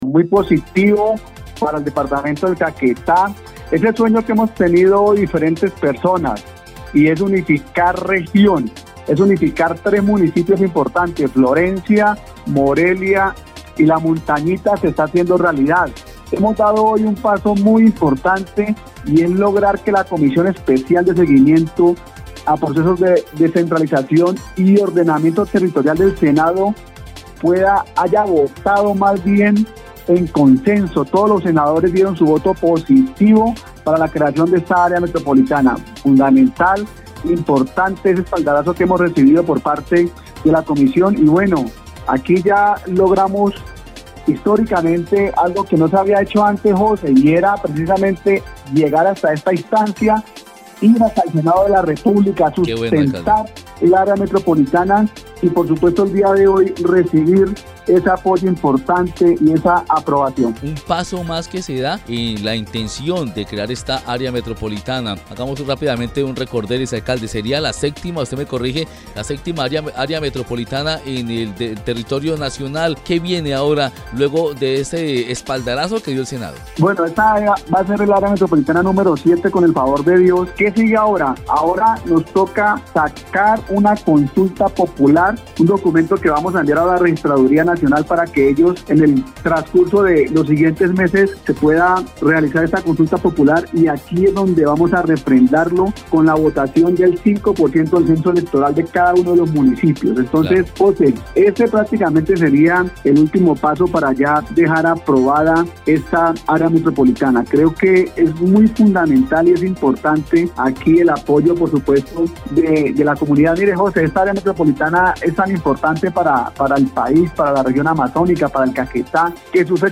Hernán Flórez Cuéllar, alcalde del municipio de Morelia, explicó que ahora viene una consulta popular para que los habitantes de estos municipios decidan en las urnas la creación o no del área metropolitana.
04_ALCALDE_HERNAN_FLOREZ_METROPOLITANA.mp3